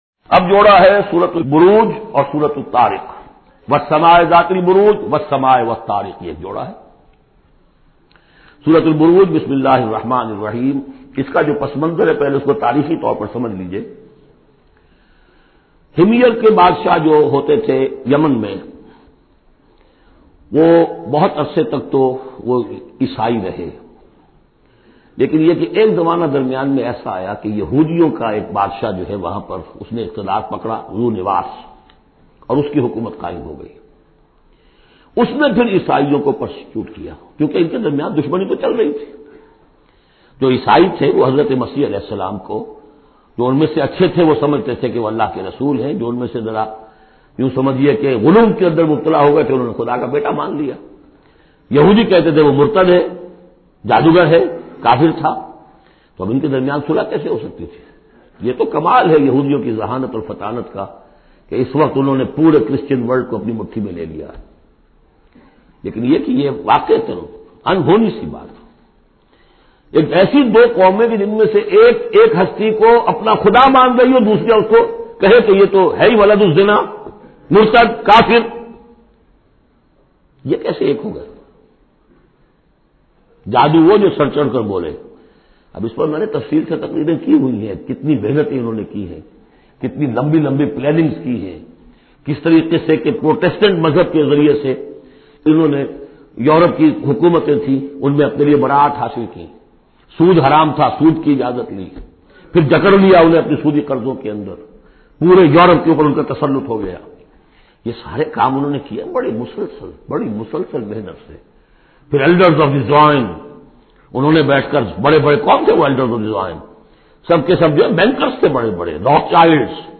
Surah Buruj Audio Tafseer by Dr Israr Ahmed
Surah Buruj is 85th chapter of Holy Quran with 22 verses or ayaats. Listen online mp3 urdu tafseer of Surah Buruj in the voice of Dr Israr Ahmed.